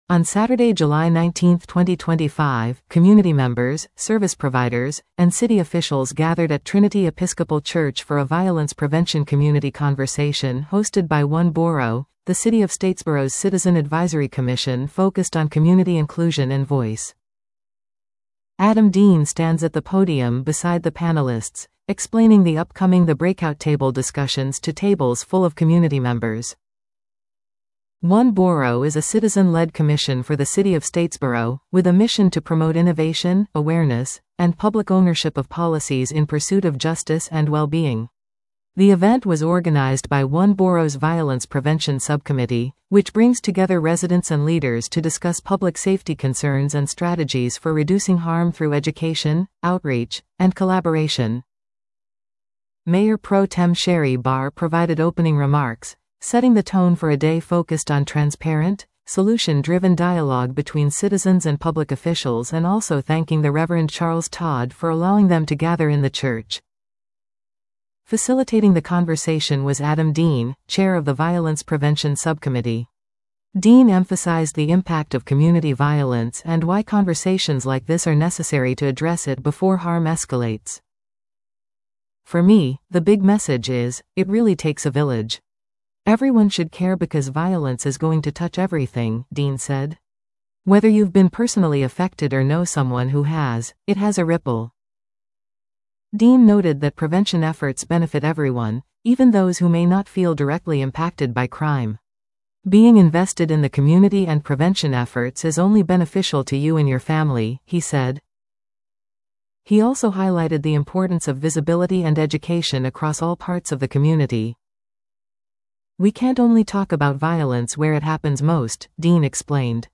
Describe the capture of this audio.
On Saturday, July 19, 2025, community members, service providers, and city officials gathered at Trinity Episcopal Church for a Violence Prevention Community Conversation hosted by One Boro, the City of Statesboro’s citizen advisory commission focused on community inclusion and voice.